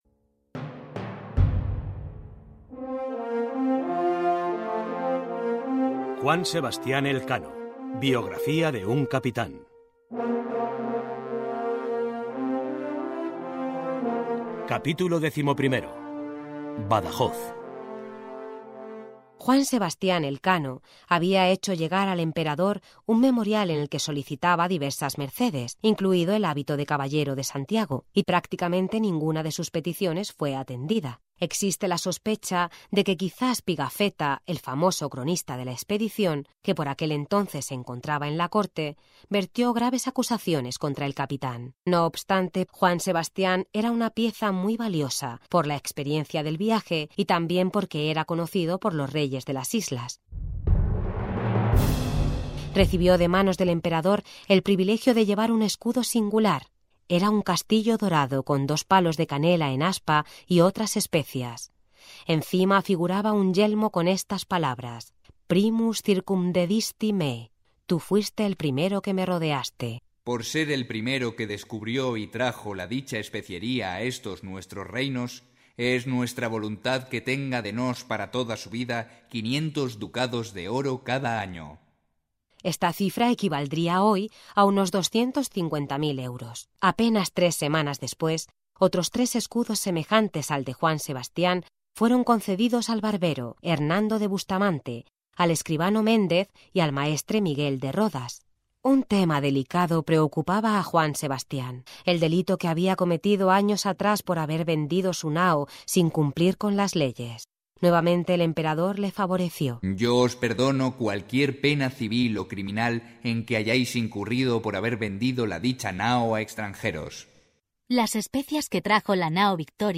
Audiolibro: Elkano biografía de un cápitan capítulo 11